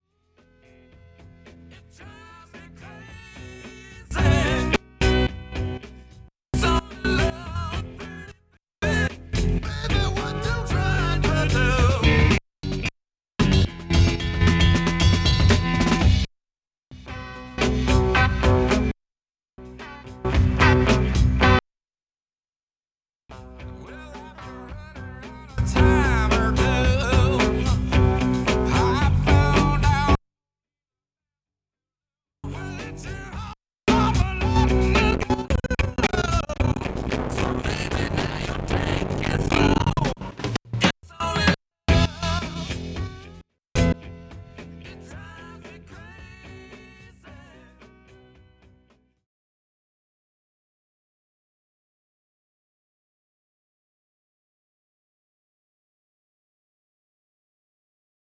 MS20 calling DFAM…